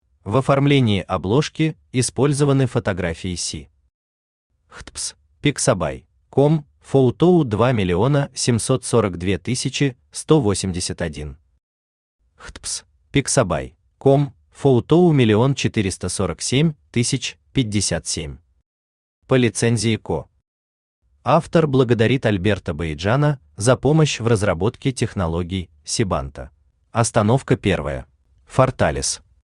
Аудиокнига Сибантийский транзит | Библиотека аудиокниг
Aудиокнига Сибантийский транзит Автор Алина Николаевна Болото Читает аудиокнигу Авточтец ЛитРес.